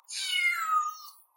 Therefore Luna meows.
cat.ogg